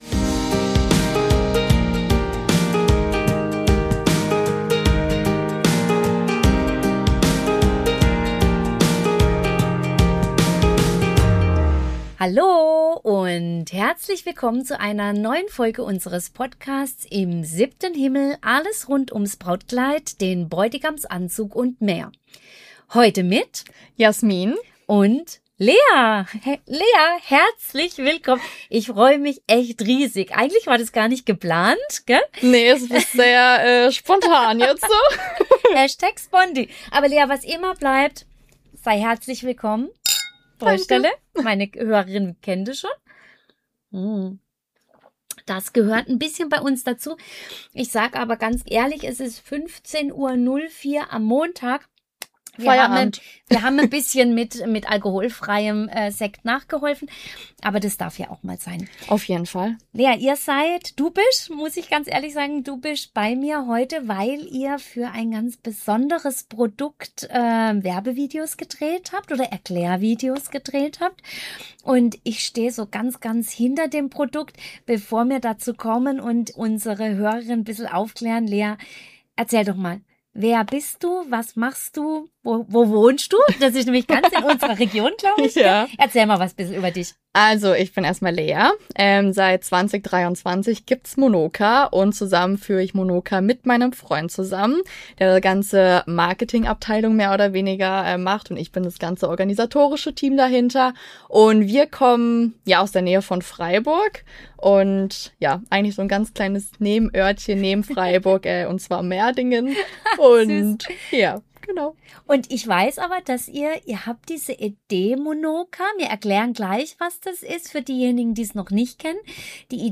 Freut euch auf ein ehrliches, spannendes und auch ein bisschen augenzwinkerndes Gespräch über Dekolleté, Passform und die kleinen Tricks, die große Wirkung haben.